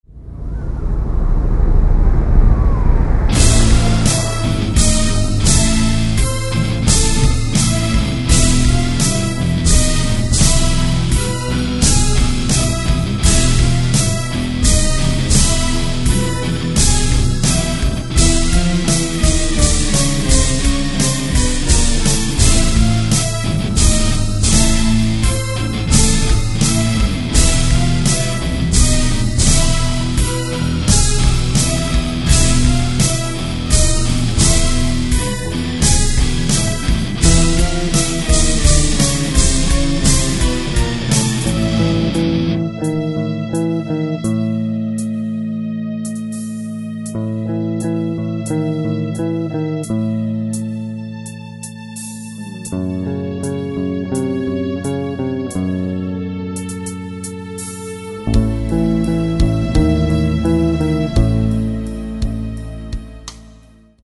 Гитары, бас, клавиши, перкуссия, голос